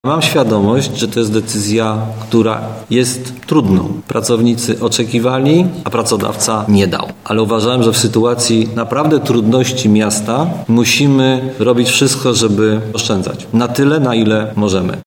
– Powiedział dziś podczas konferencji prasowej prezydent Tarnobrzega.